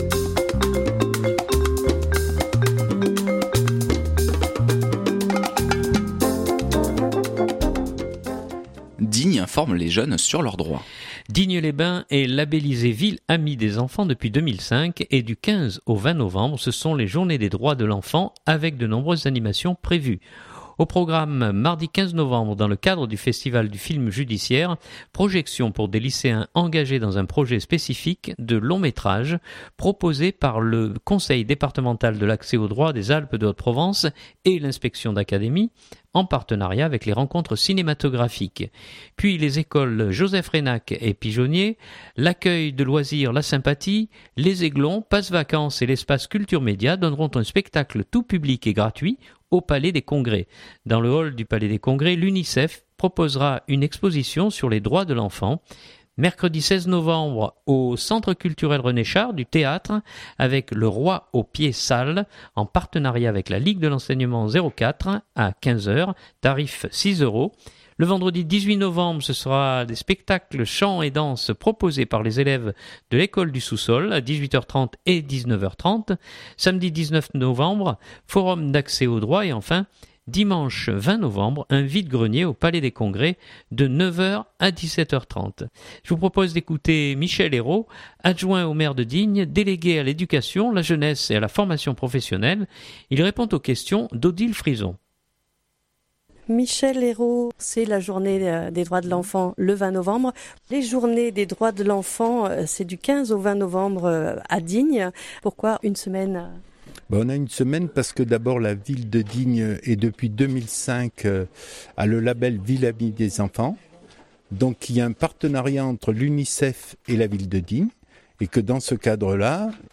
Je vous propose d’écouter Michel Eyraud, Adjoint au maire, délégué à l’éducation, la jeunesse et à la formation professionnelle.